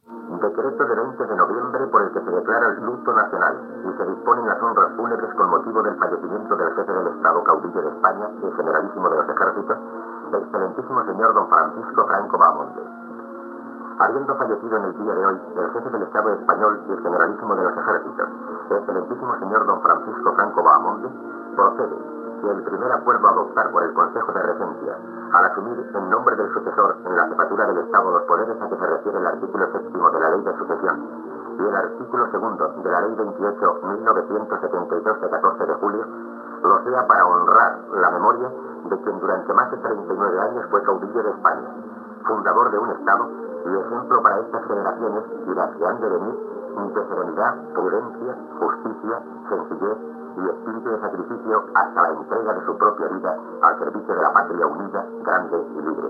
Informatiu especial de les 22 hores.
Informatiu